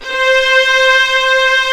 55m-orc11-C4.wav